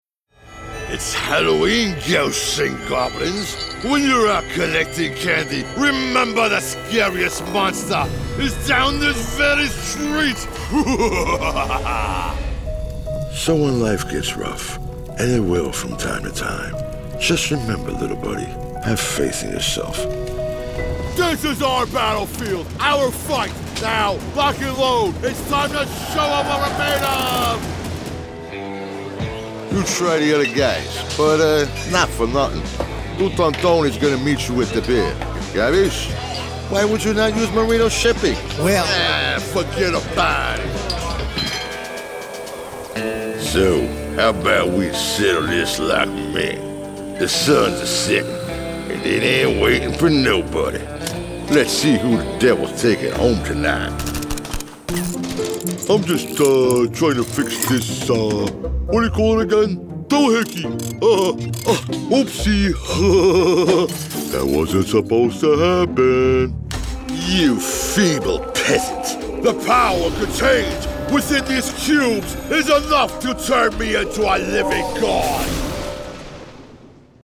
Male
Voice is Deep, Distinctive, and Authoritative, with a Commanding Presence that exudes Confidence and Power.
Character / Cartoon
Monster,Wizard,Cowboy,Gangster